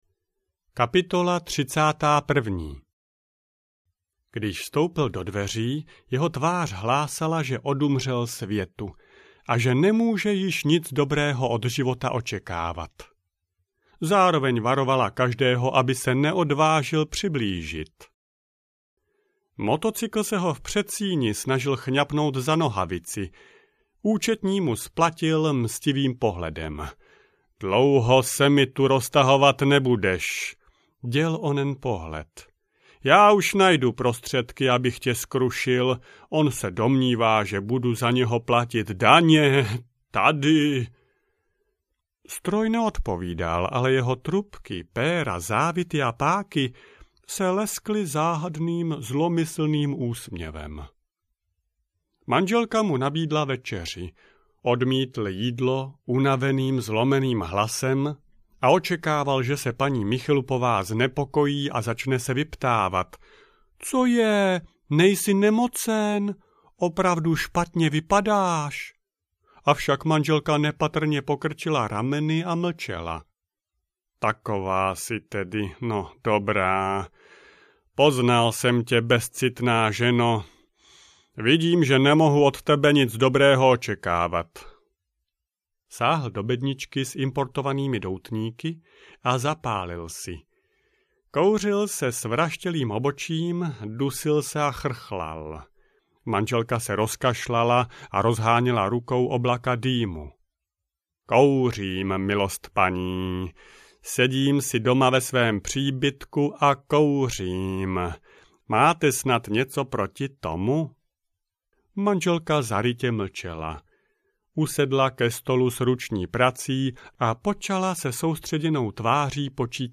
Michelup a motocykl audiokniha
Ukázka z knihy